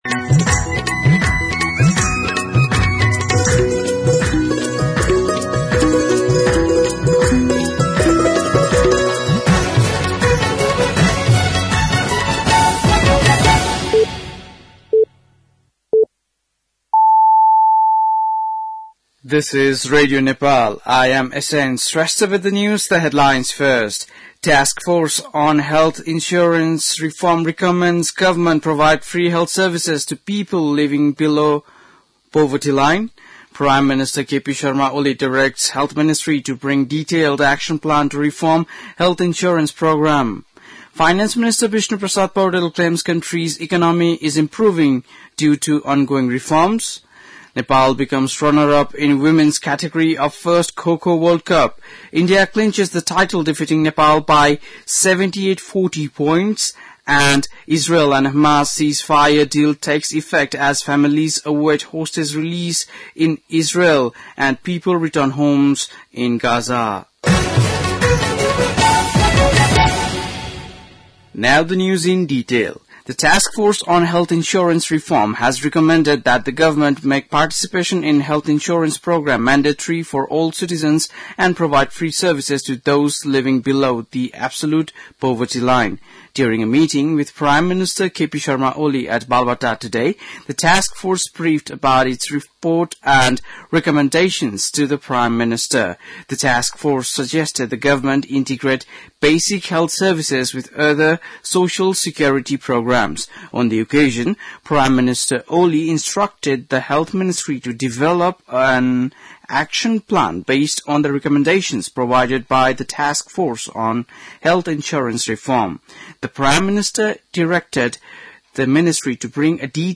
बेलुकी ८ बजेको अङ्ग्रेजी समाचार : ७ माघ , २०८१
8-PM-English-News-10-6.mp3